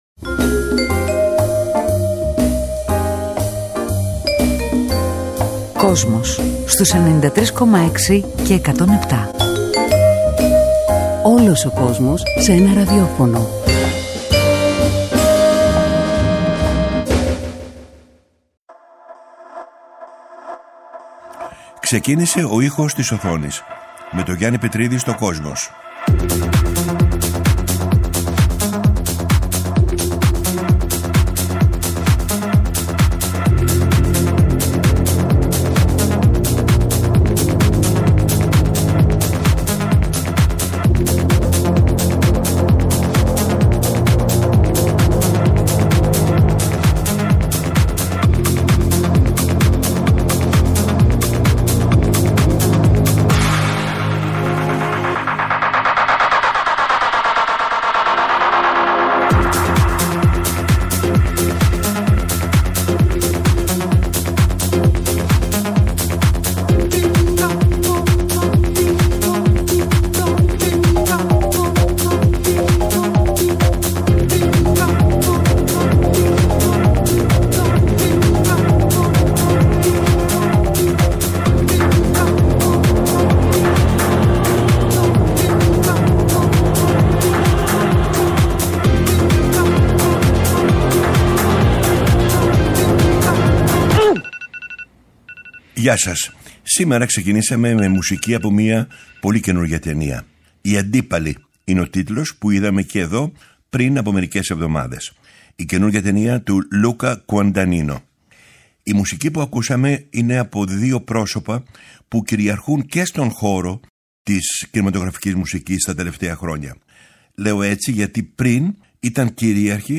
μουσική από τις ταινίες